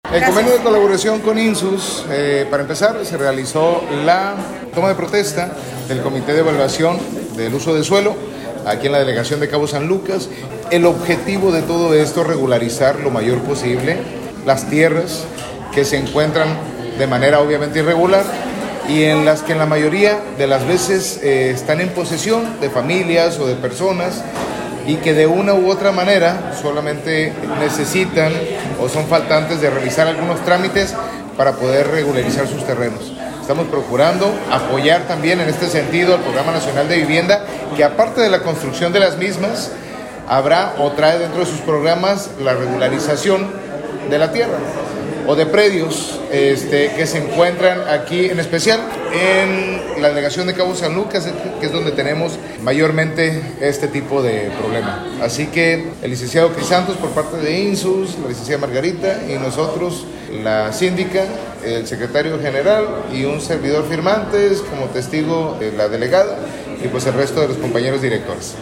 Christian Agúndez Gómez – Alcade de Los Cabos
Alcalde-Christian-Agundez-Firma-de-convenio-CSL.mp3